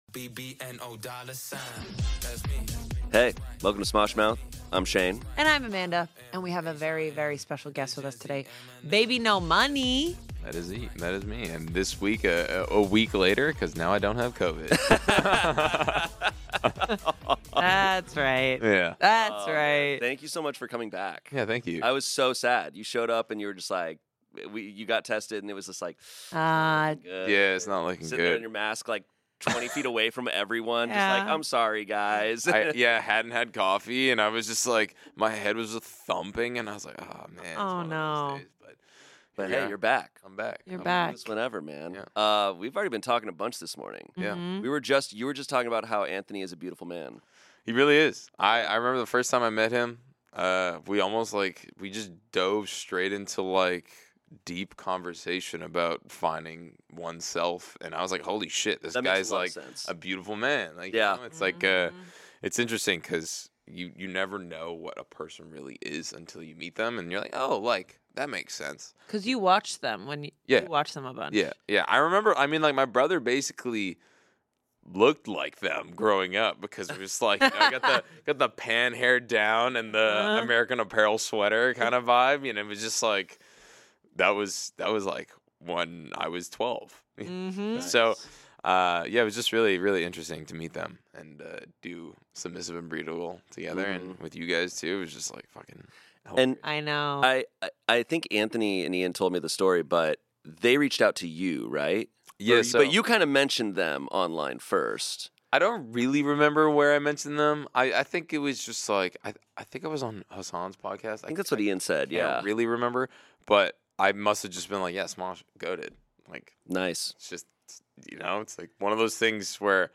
Submissive and Breedable ICON bbno$ joins Amanda and Shayne to chat about music!